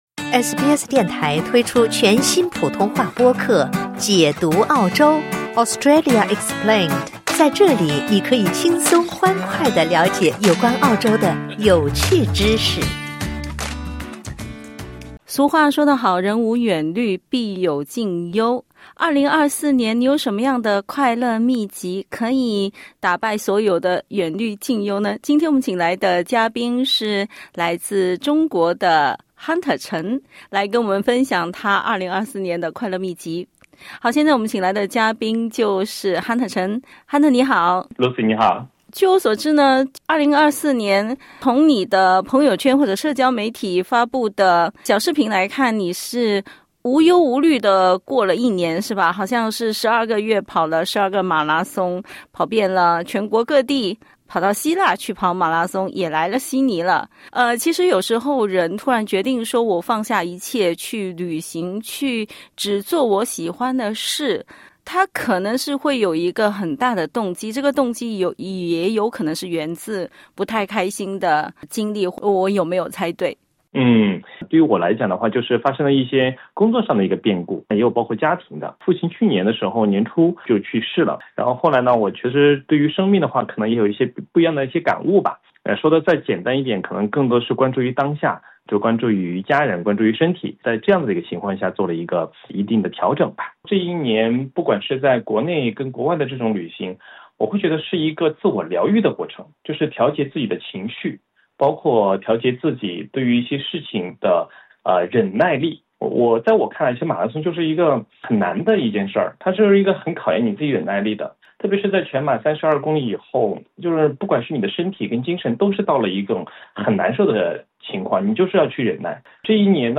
（点击音频收听详细采访） 2024的你还快乐吗？